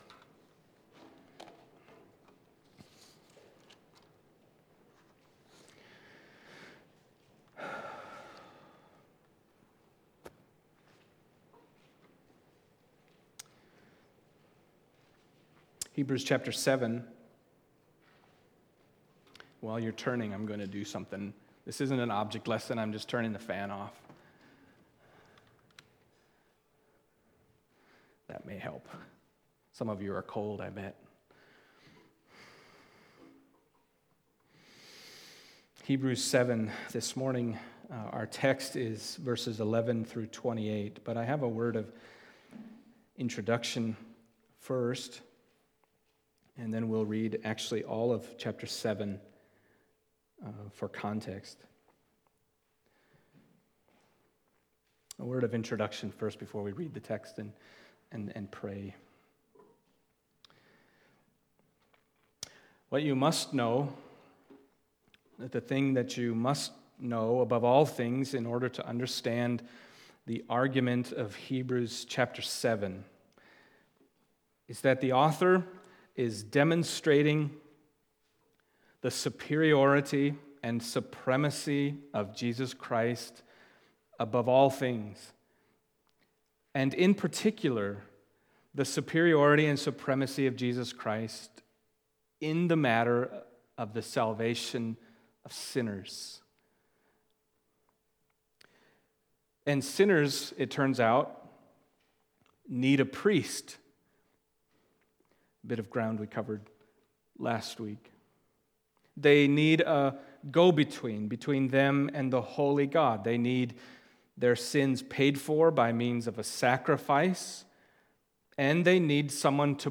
Hebrews Passage: Hebrews 7:11-28 Service Type: Sunday Morning Hebrews 7:11-28 « What’s With Melchizedek?